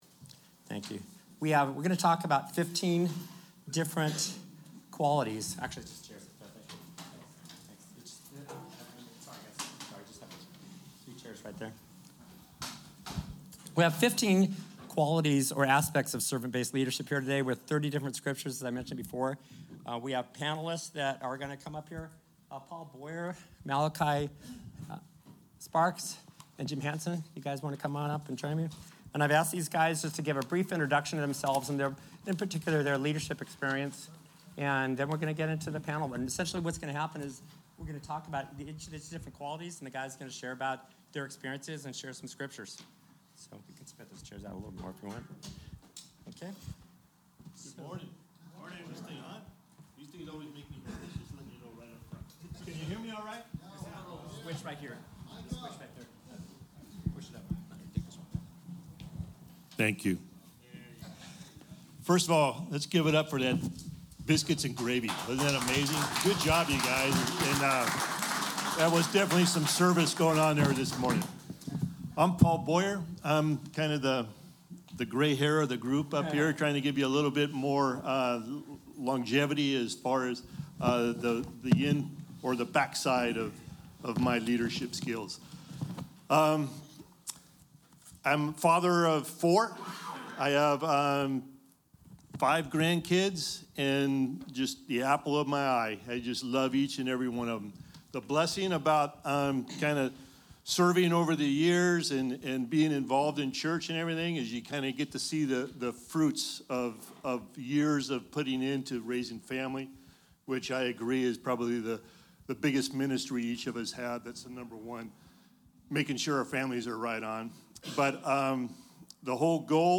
BCC-Mens-Confrence-QA.mp3